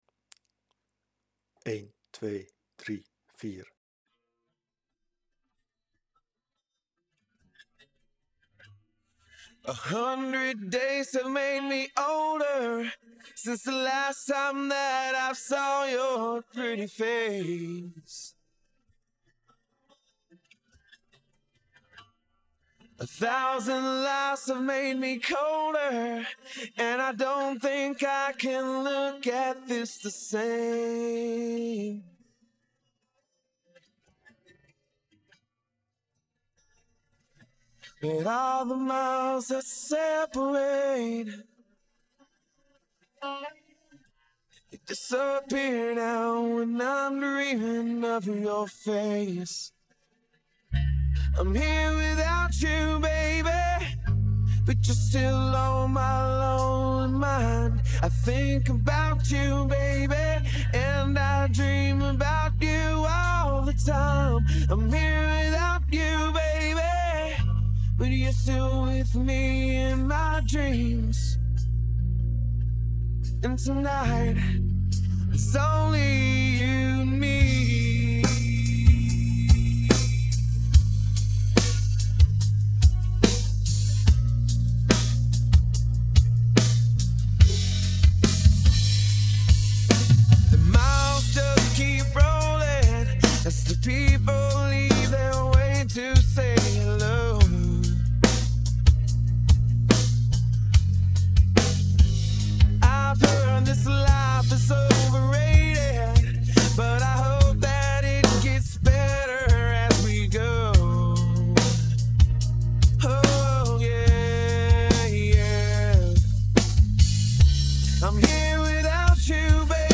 The backingtrack starts with four beats.